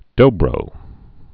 (dōbrō)